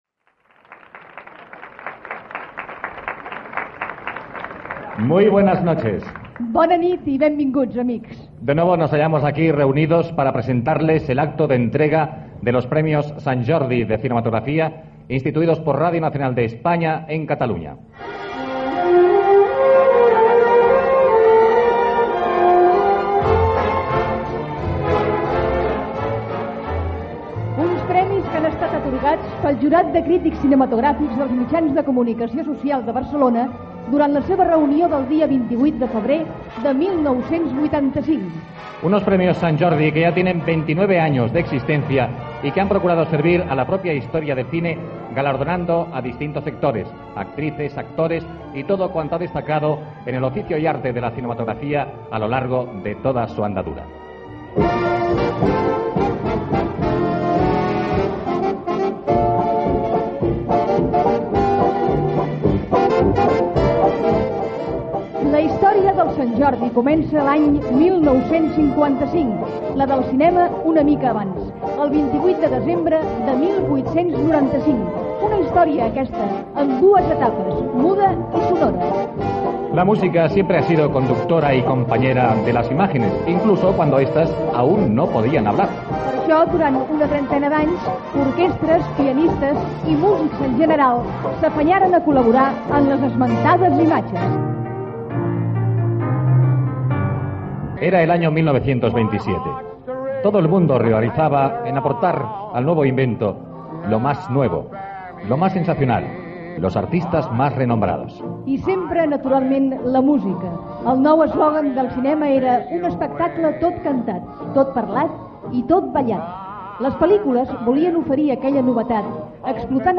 Acte de lliurament dels XXIX Premis Sant Jordi de Cinematografia des de la sala Scala de Barcelona. Dates significatives de la història del cinema.